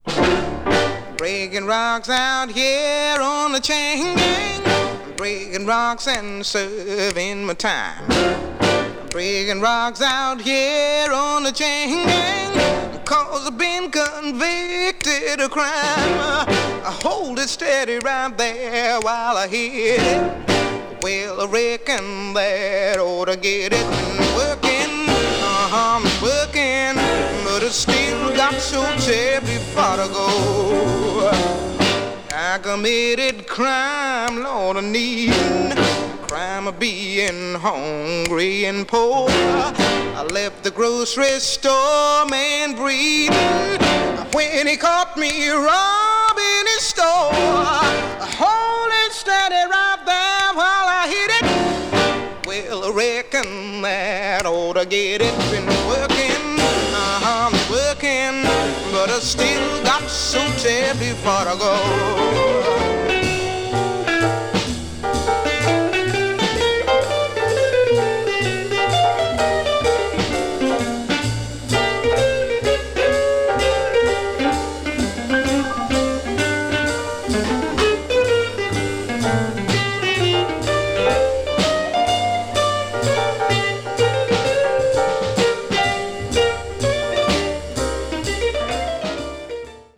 Mono
bles jazz   blues   jazz vocal   soul jazz